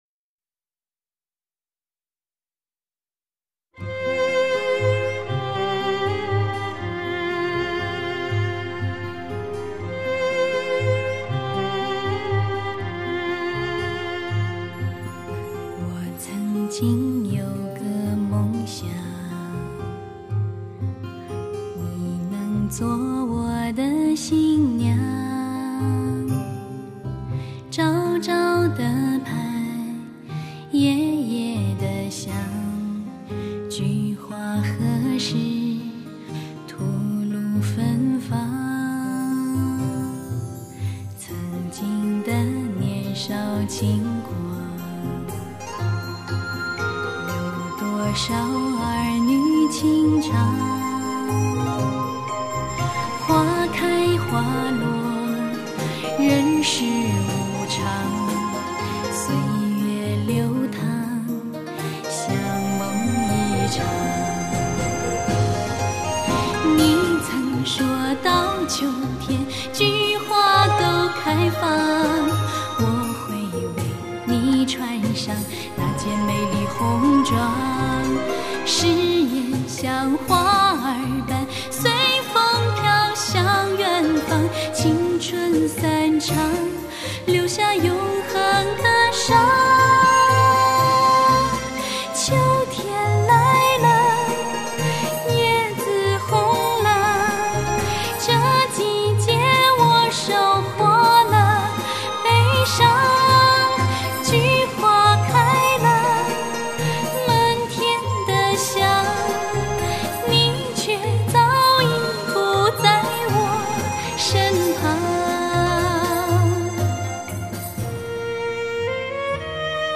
年度最佳流行发烧情歌，以完美颗粒的音乐和全无漏接的音效采集技术，空灵飘渺。